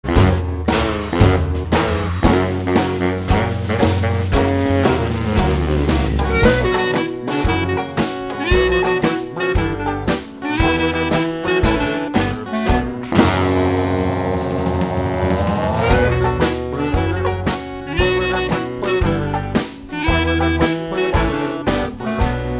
Без слов